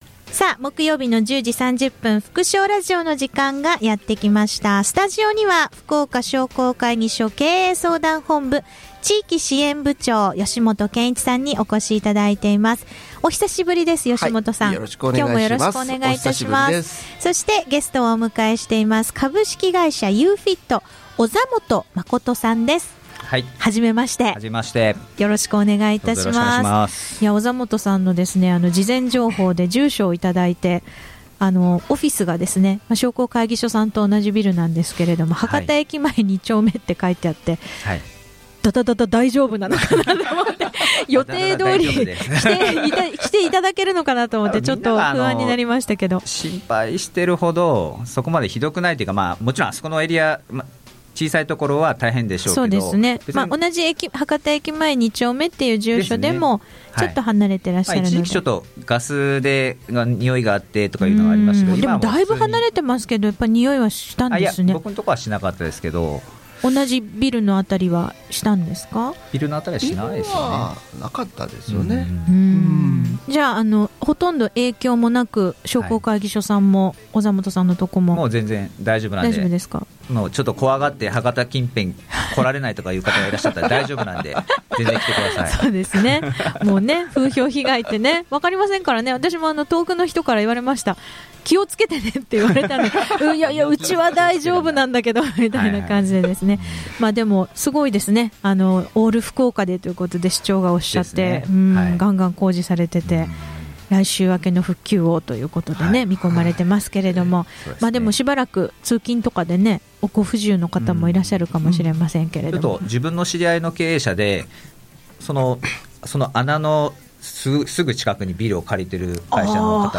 吸音材に囲まれた場所に行ってきました！